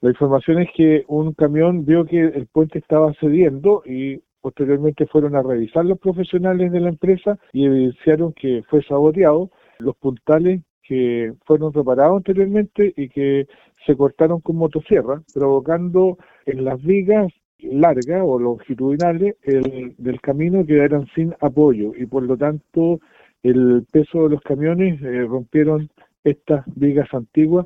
El alcalde de Mariquina, Rolando Mitre, señaló que la empresa informó de un “sabotaje” y explicó que el puente cedió porque las vigas longitudinales NO soportaron el paso de los vehículos.